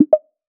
Ui Click Bubble 02.wav